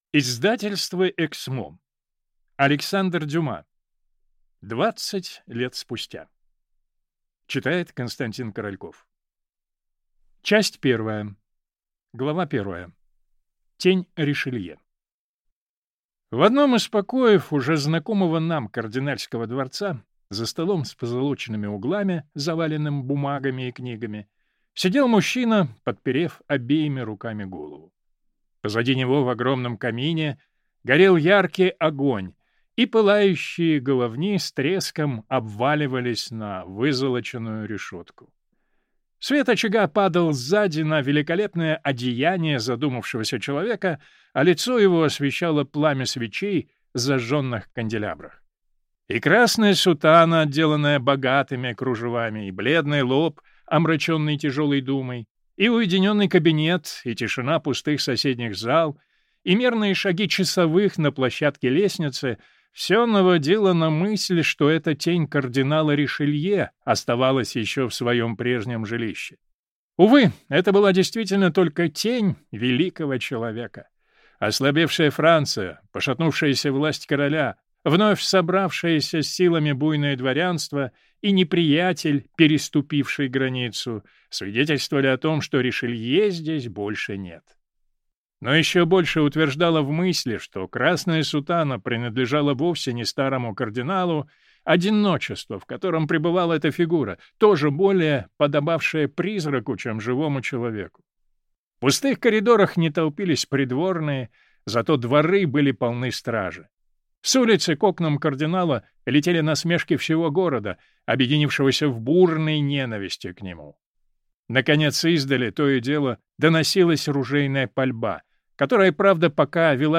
Аудиокнига Двадцать лет спустя | Библиотека аудиокниг